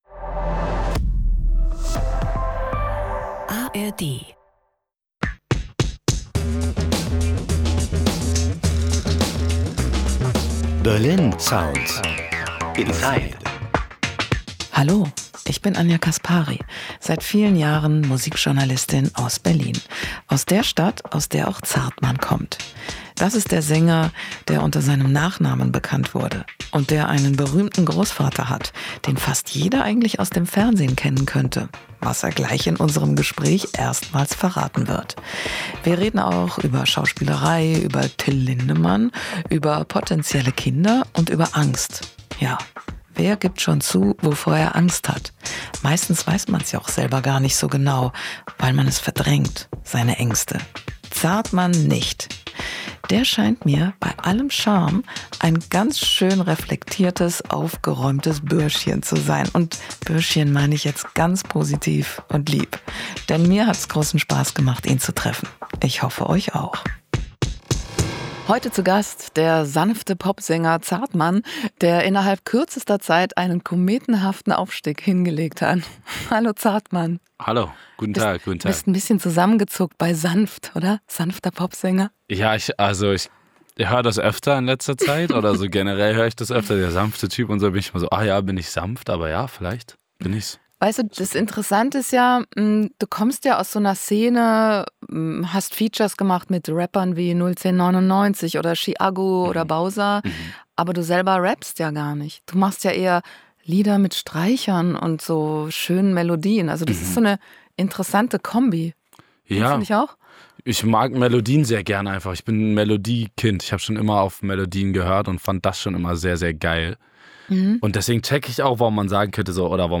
Normalerweise redet "das Melodiekind", wie er sich im Interview nennt, nicht gerne über Privates.